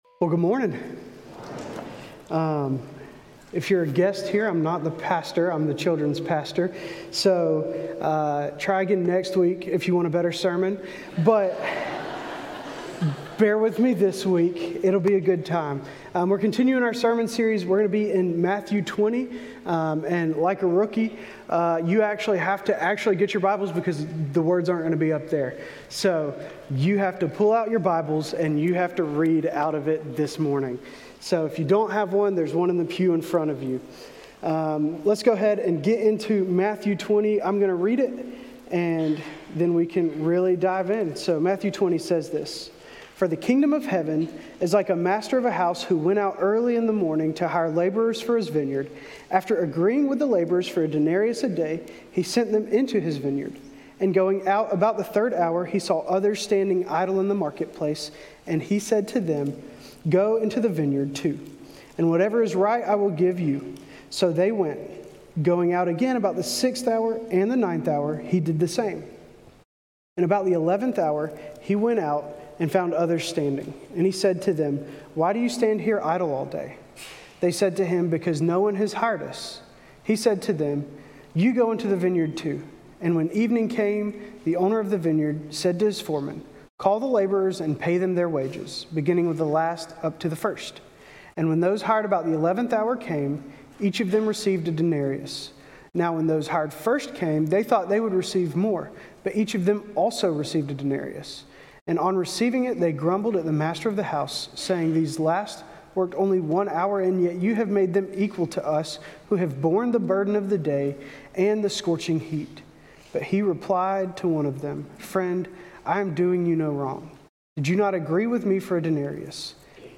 A message from the series "We're Not Home Yet."